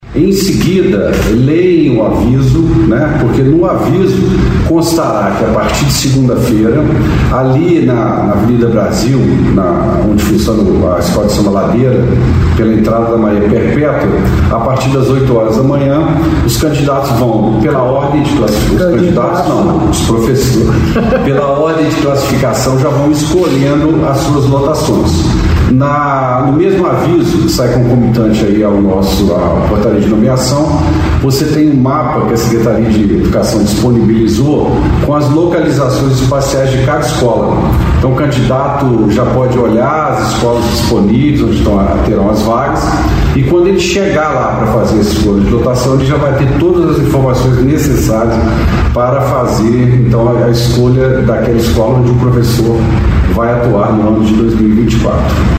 É importante que o candidato consulte o cronograma no site da Prefeitura com as datas e horários, uma vez que a distribuição de dias e horários foi gerada conforme a ordem de classificação final de cada edital. É o que detalha o secretário de Recursos Humanos, Rogério de Freitas.